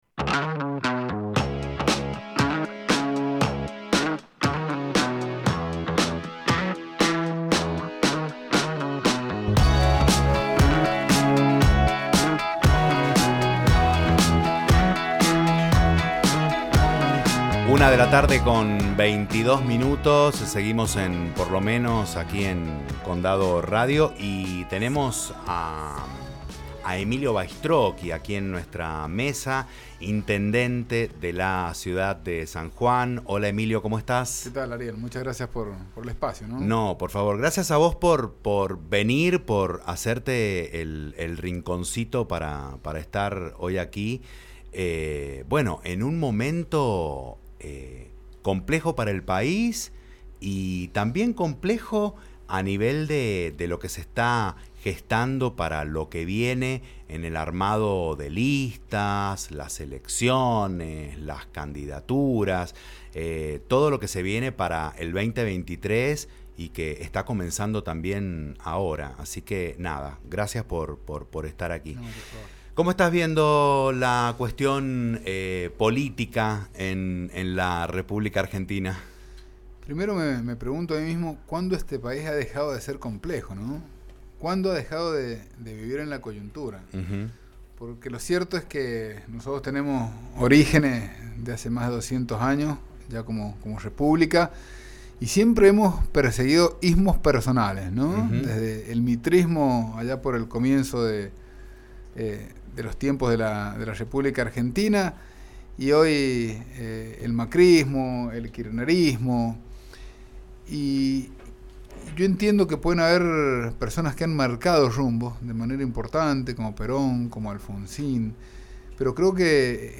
El actual intendente de la Ciudad de San Juan se expresó en Condado Radio San Juan sobre la situación del departamento y la renovación de su mandato.
ENTREVISTA-EMILIO-BAITROCCHI.mp3